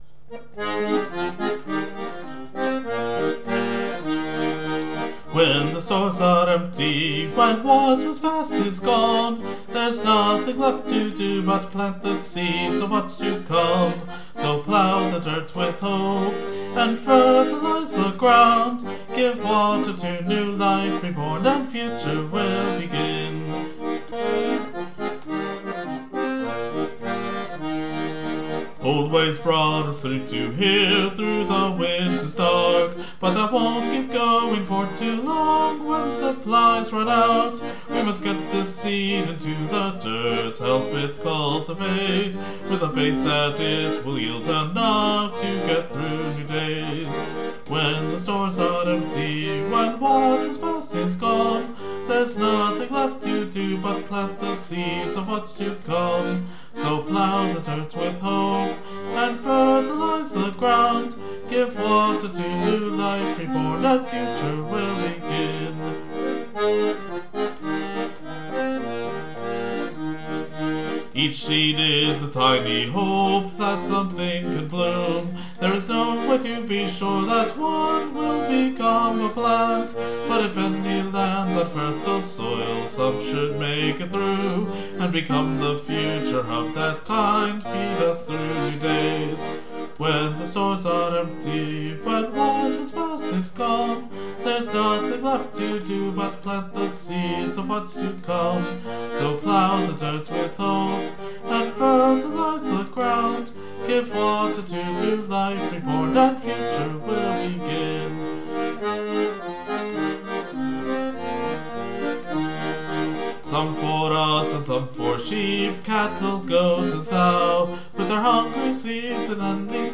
Musically, this is a fairly simple polka, although a bit more complicated harmonically on the old squeezebox than it might look at first. Lyrically, this isn't just about planting food, but also about finding new ways when the old ways stop working for you and there's nothing left to do but toss whatever seeds you can out into the world to see what thrives.